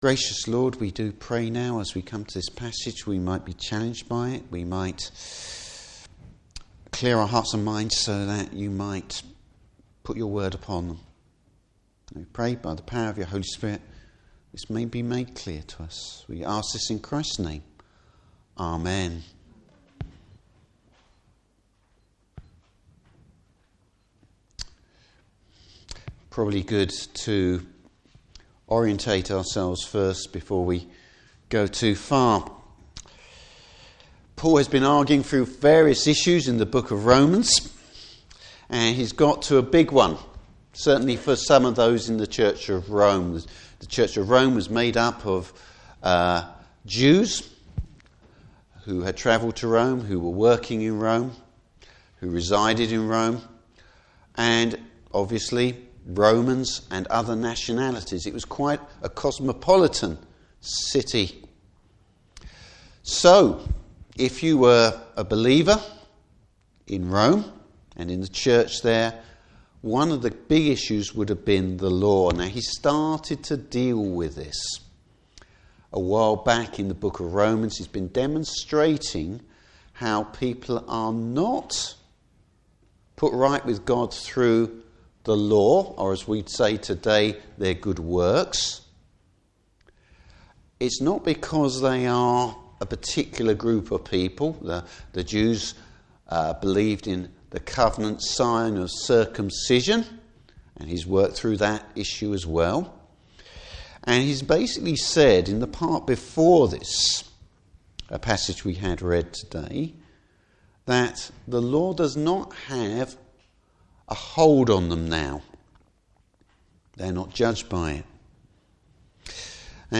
Service Type: Morning Service Is the Law bad?